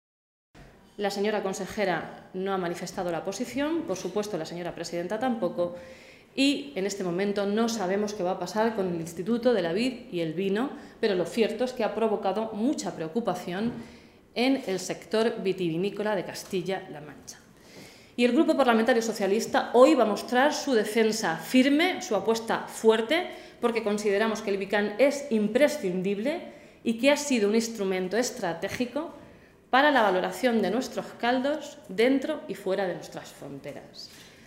Blanca Fernández, diputada regional del PSOE de C-LM
Cortes de audio de la rueda de prensa